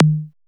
RES TOM.wav